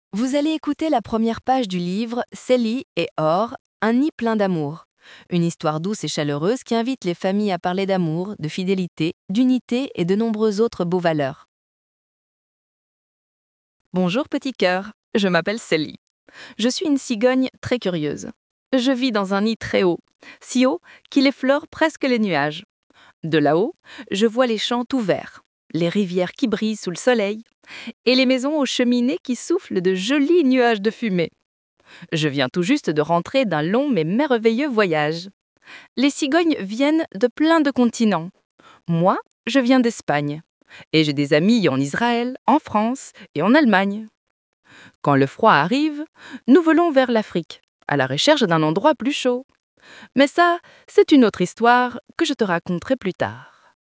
Lectures (audio)